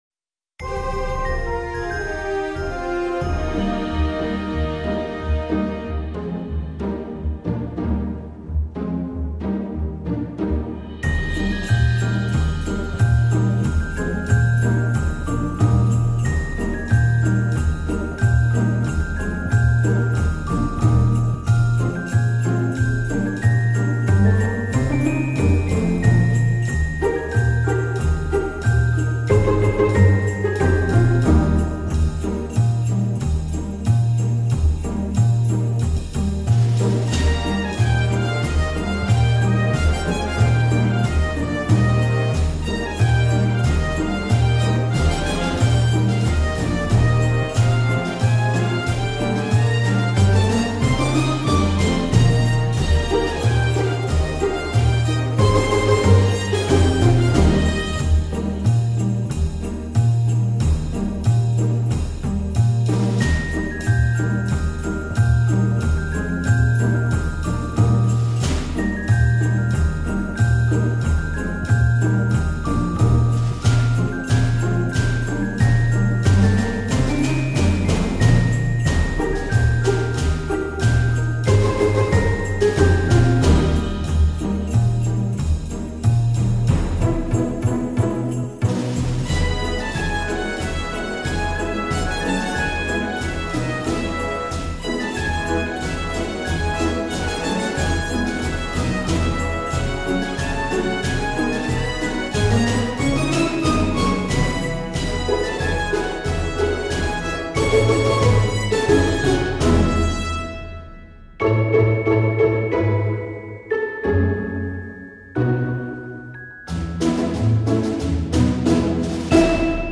Deck the Halls - Instrumental.mp3